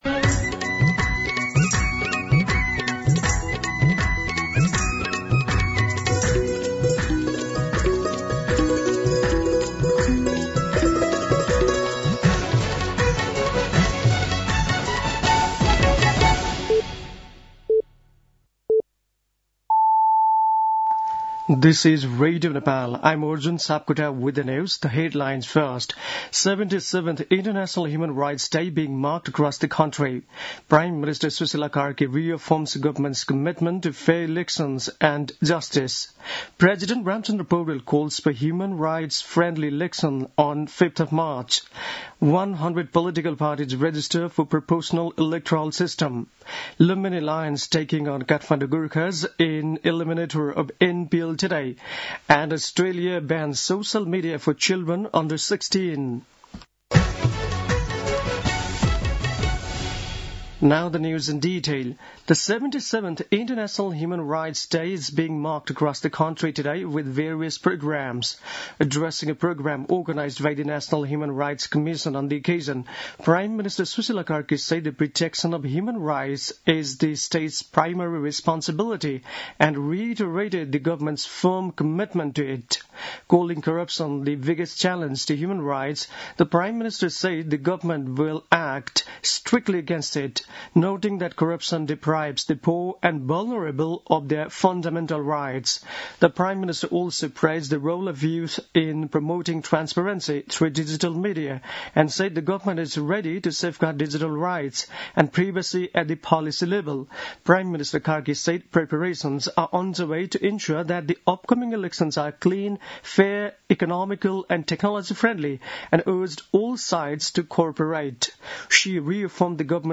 दिउँसो २ बजेको अङ्ग्रेजी समाचार : २४ मंसिर , २०८२